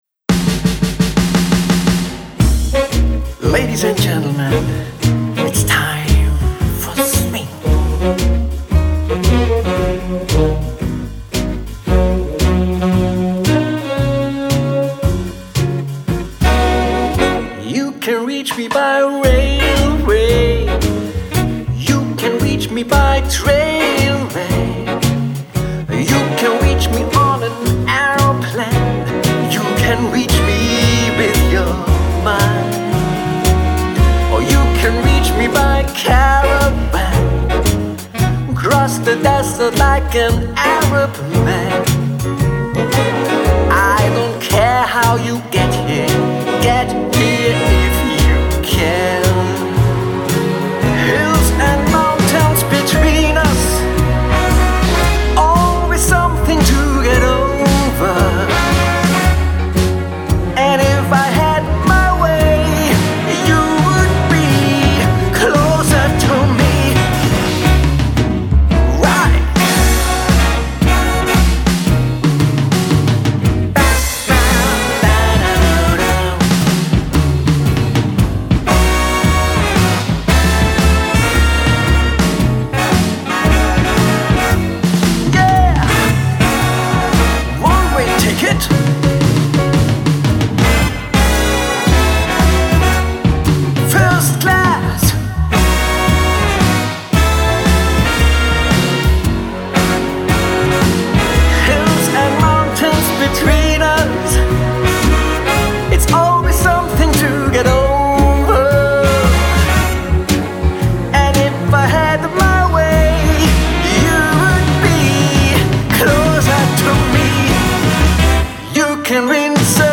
Genre: Event Entertainment